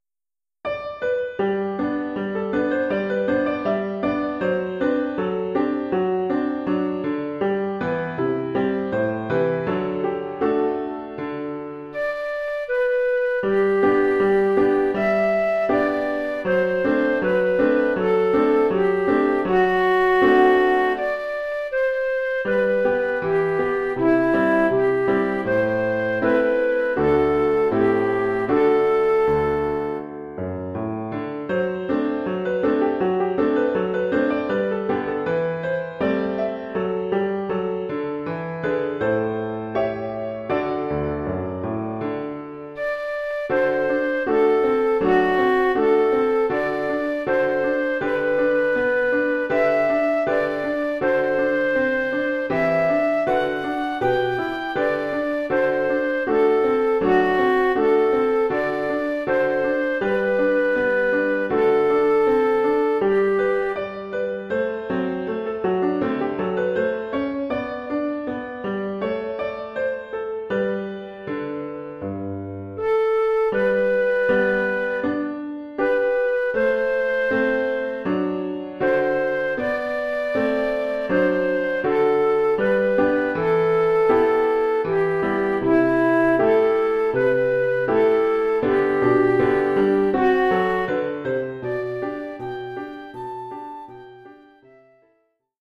Oeuvre pour flûte traversière et piano.